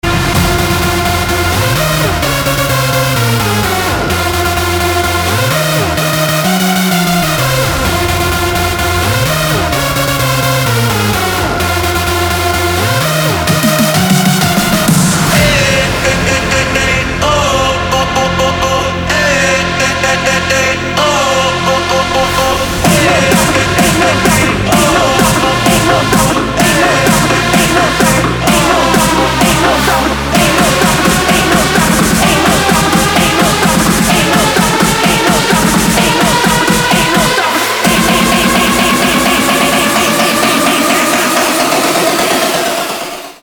• Качество: 320, Stereo
dance
club
electro house